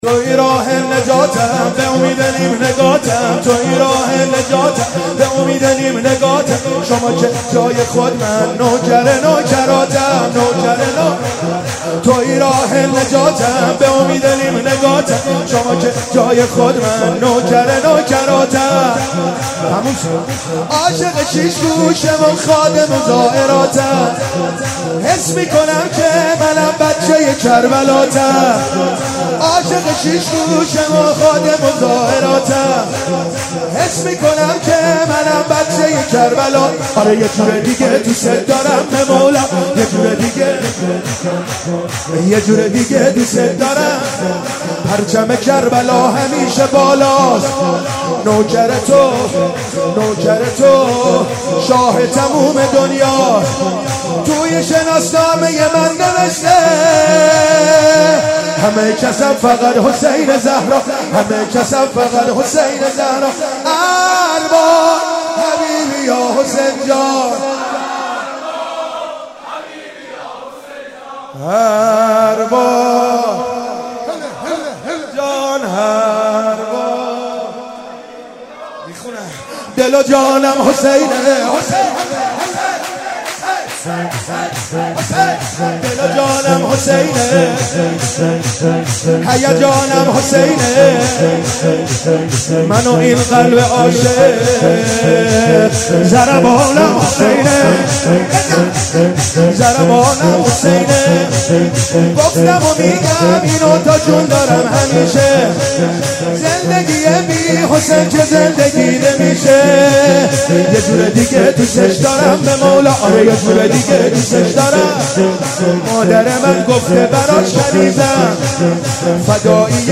با مدیحه سرایی
در مجتمع فرهنگی امام رضا (ع) برگزار گردید